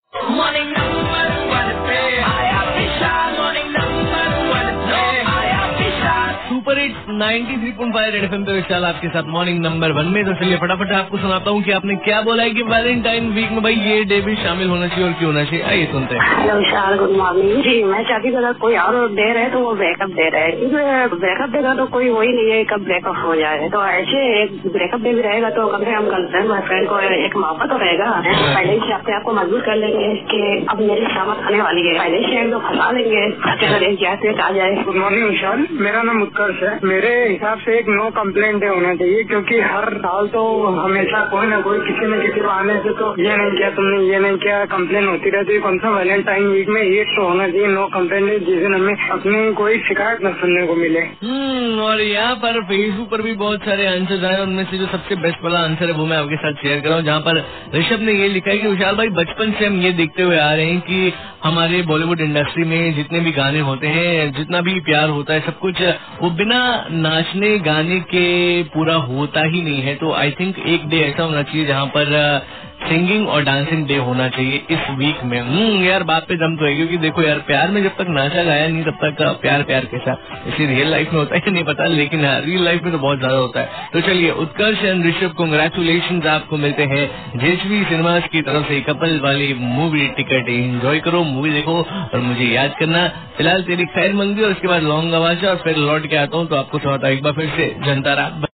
caller byte2